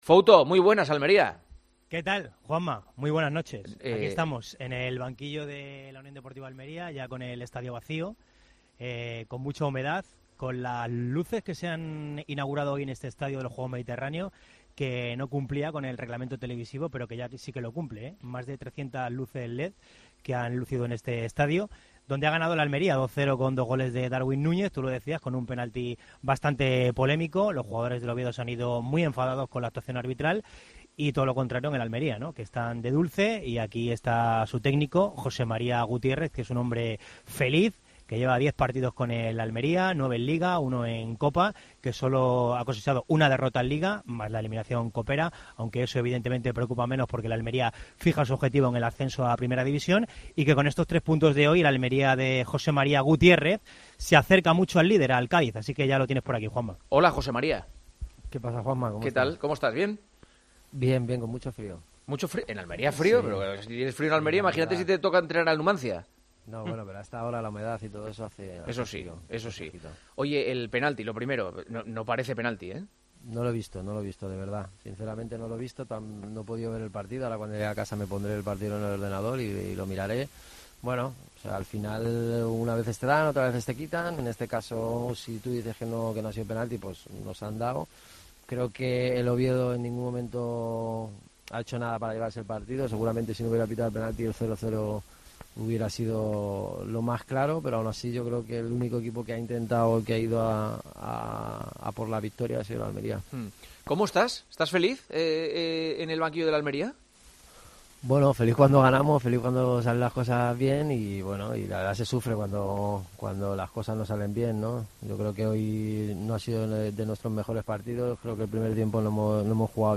El entrenador del Almería ha repasado la actualidad del fútbol español, en El Partidazo de COPE.